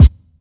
• Present Kick Sound E Key 117.wav
Royality free kick drum single shot tuned to the E note.
present-kick-sound-e-key-117-lqt.wav